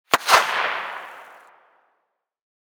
Incendiary_Far_02.ogg